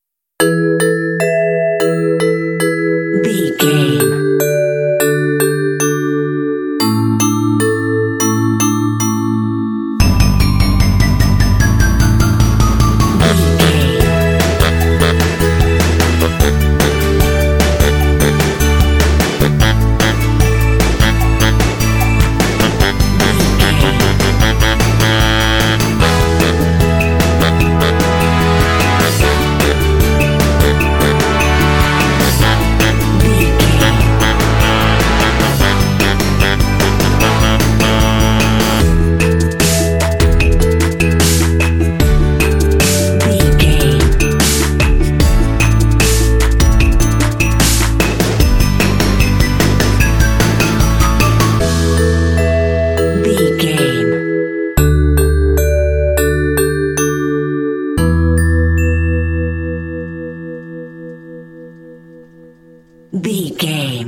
Uplifting
Ionian/Major
Fast
energetic
electric piano
drums
synthesiser
saxophone
percussion
playful
pop
contemporary underscore